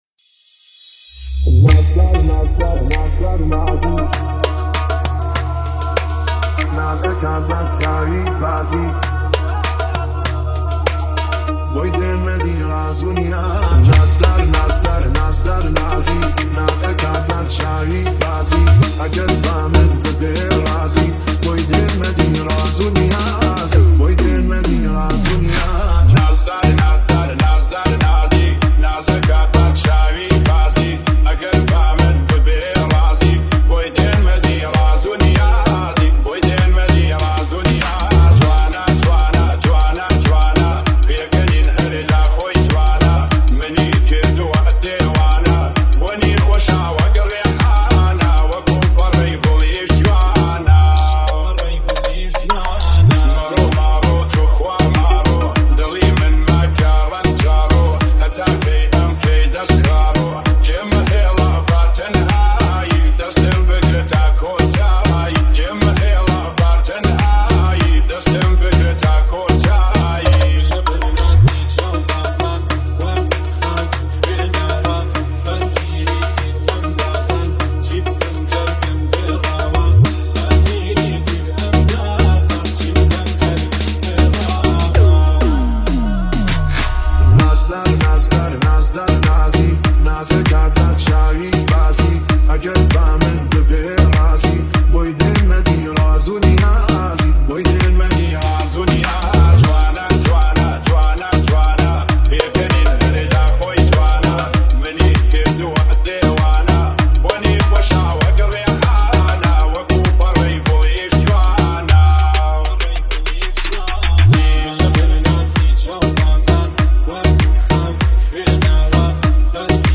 893 بازدید ۲۱ آذر ۱۴۰۲ ریمیکس , ریمیکس کردی